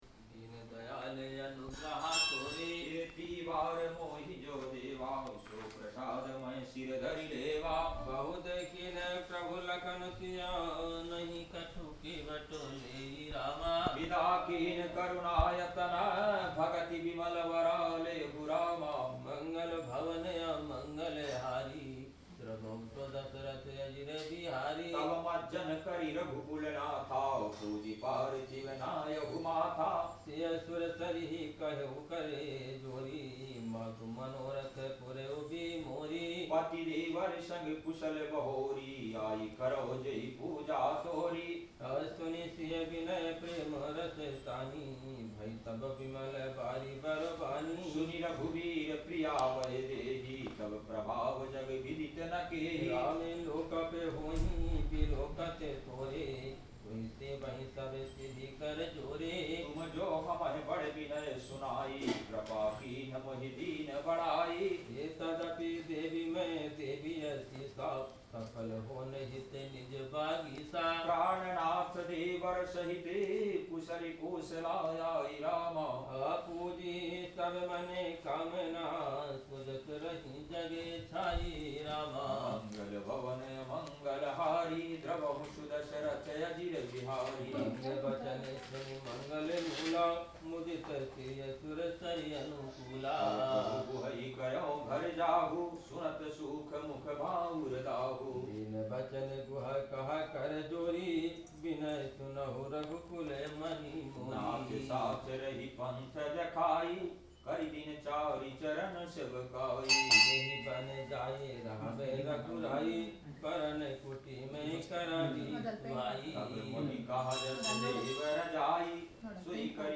priere_monkey.mp3